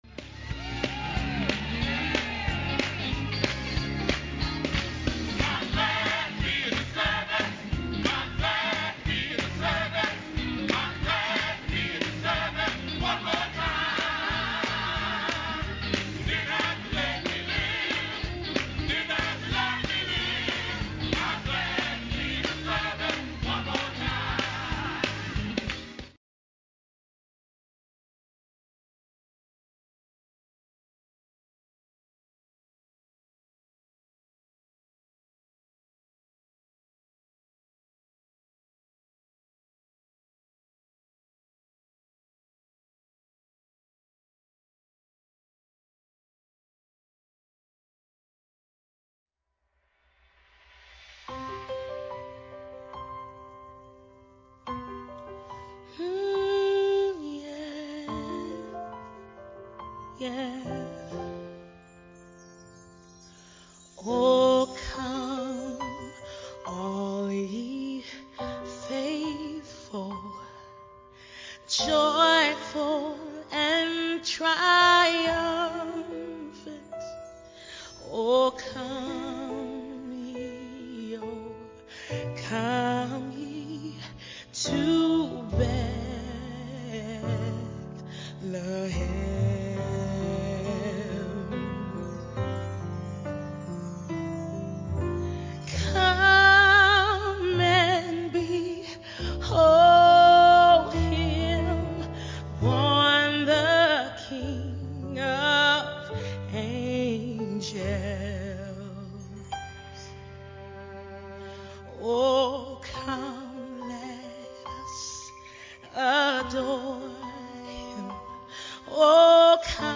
7:30 A.M. Service: God’s Greatest Gift